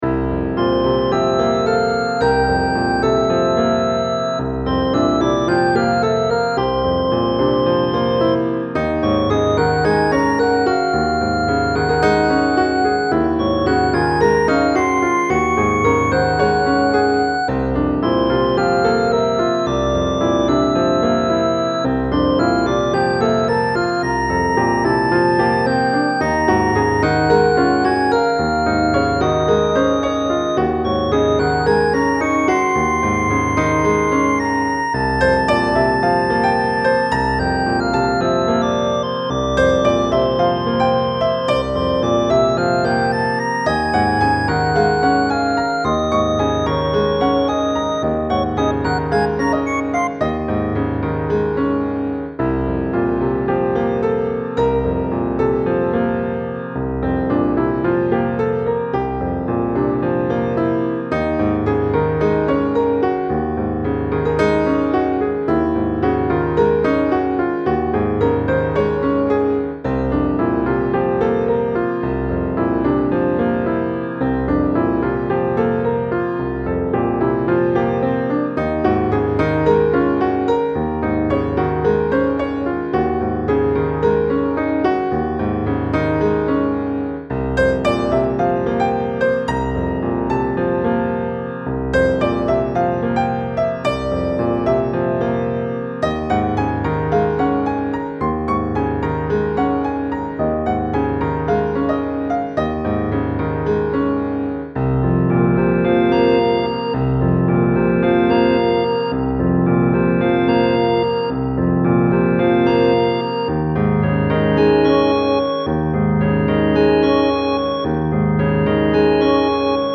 Dúo órgano-piano
piano
órgano
Sonidos: Música